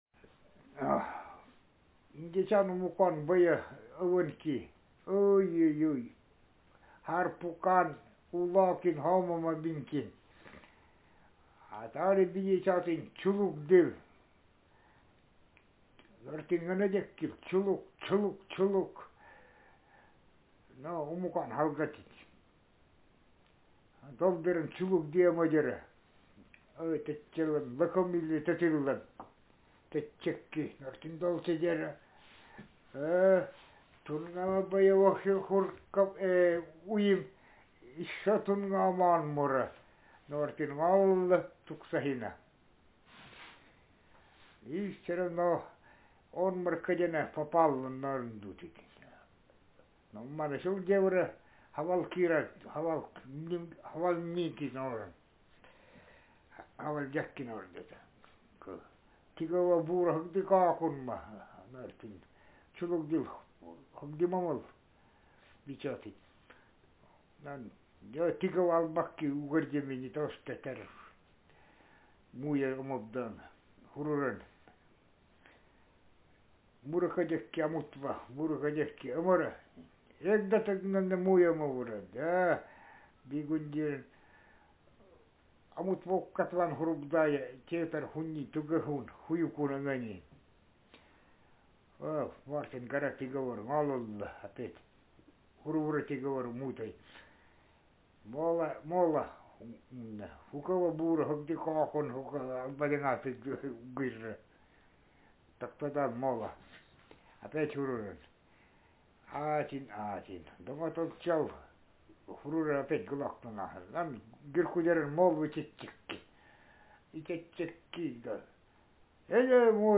Speaker sexm
Text genrepersonal narrative